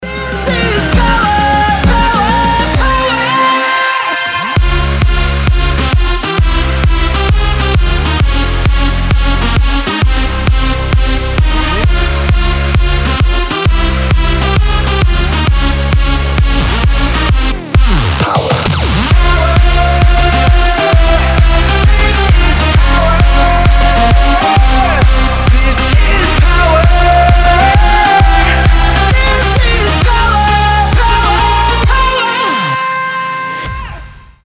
мужской вокал
громкие
dance
Electronic
EDM
club
Big Room
Стиль: big room